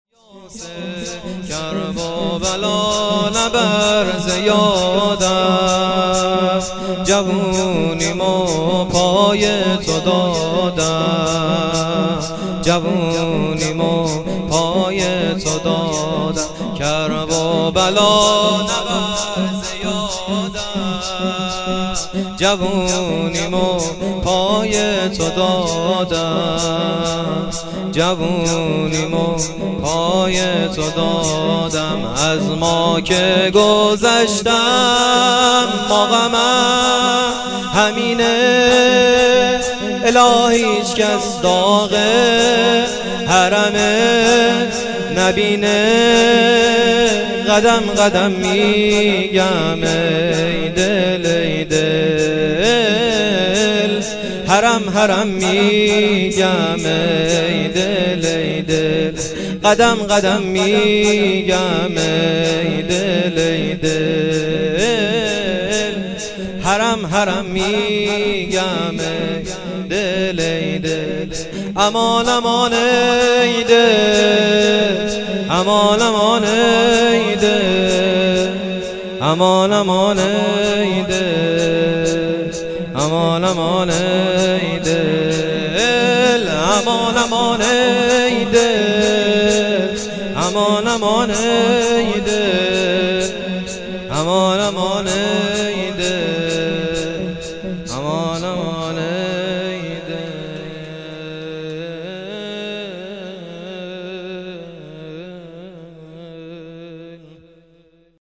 شور - کرب و بلا نبر ز یادم